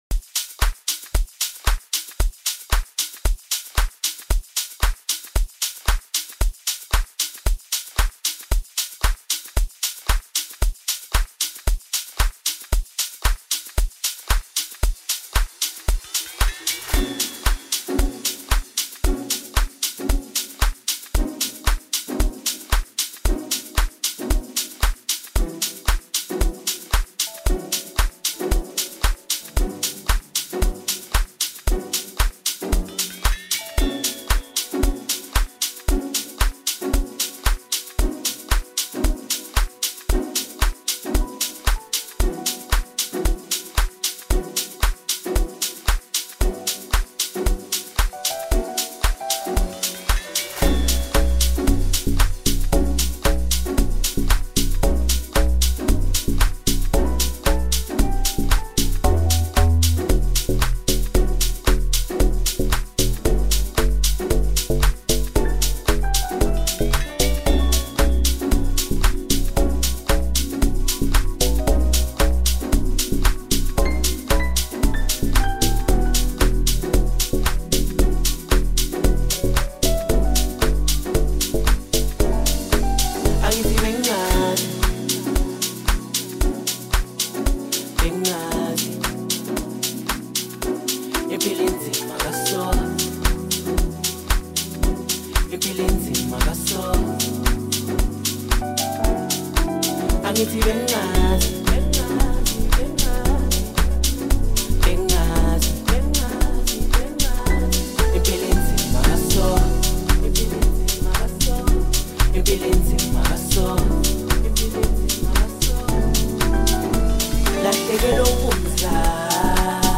Amapiano, DJ Mix, Hip Hop